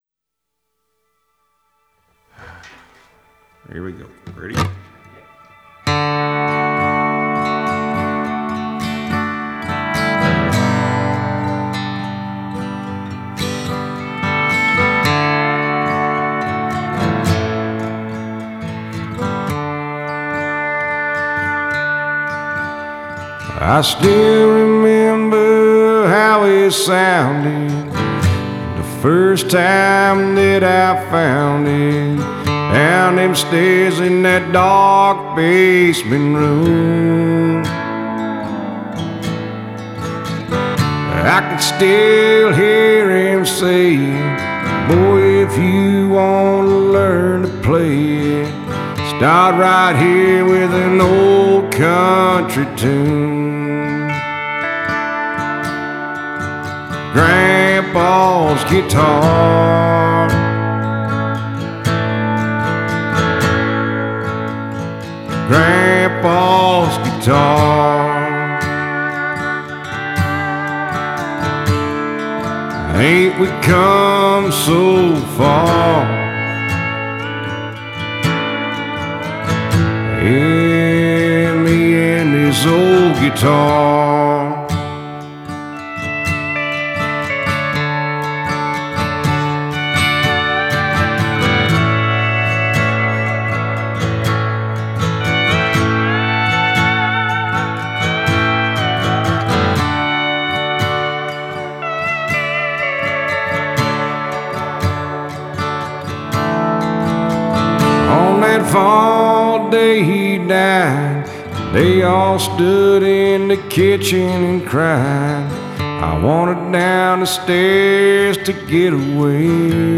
Recorded in hotel rooms across America